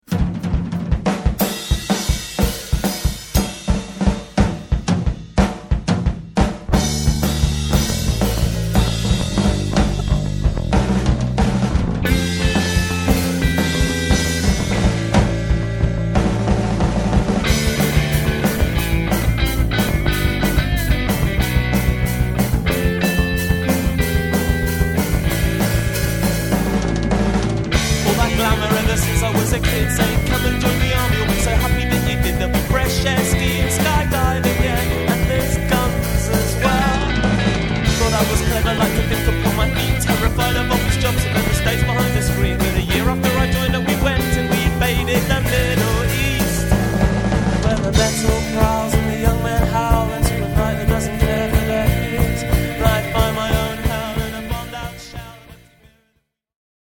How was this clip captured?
Recordings made at our studio facilities.